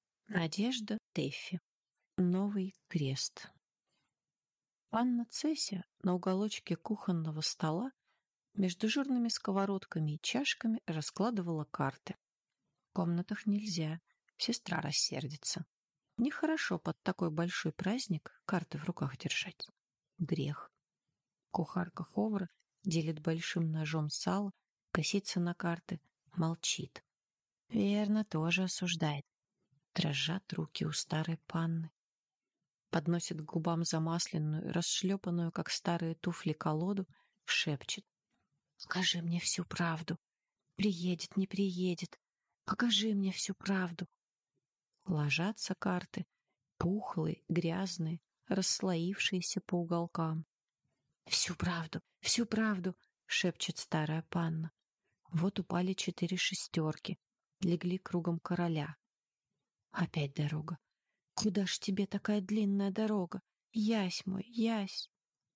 Аудиокнига Новый крест | Библиотека аудиокниг